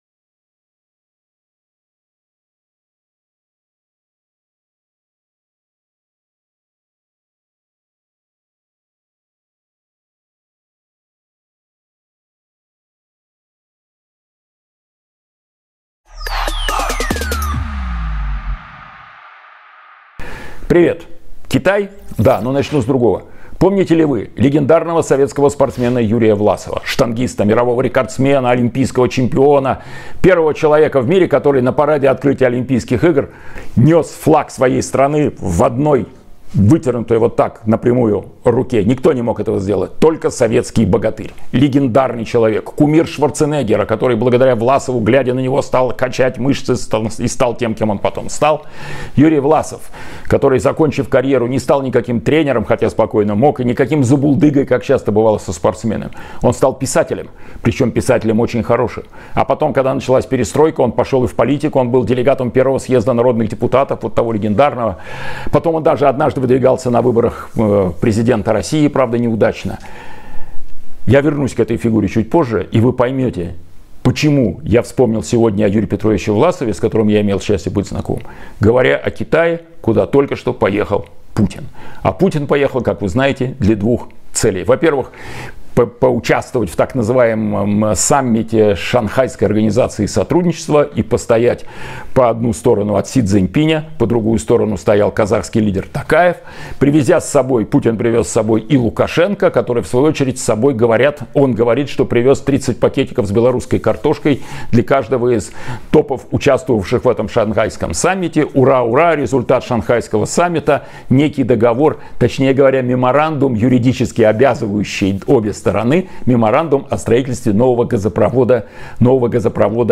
Эфир ведёт Кирилл Набутов